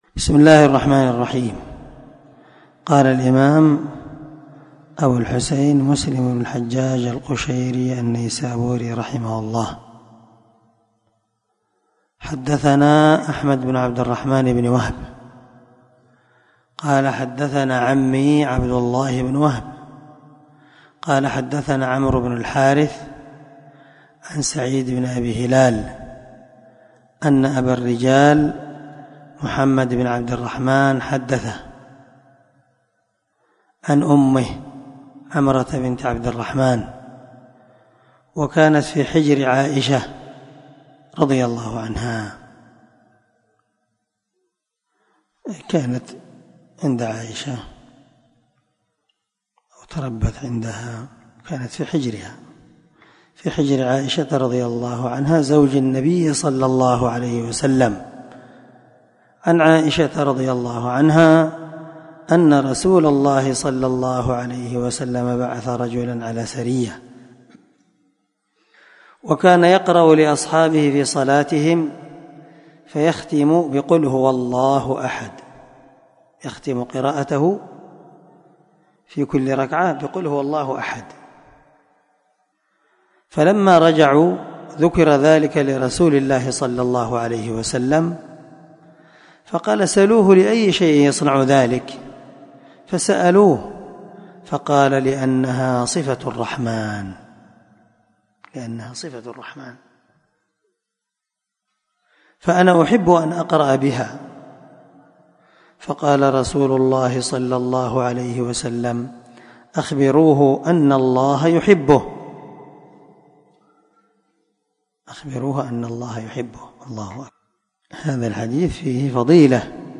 494الدرس 62 شرح كتاب صلاة المسافرين وقصرها حديث رقم (813)صحيح مسلم